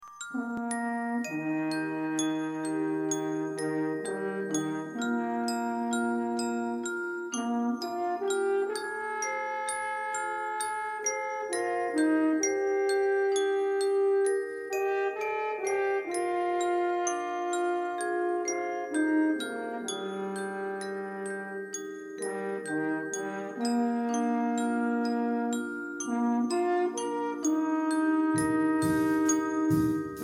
Trompette Basse Mib
Trompette_basse.mp3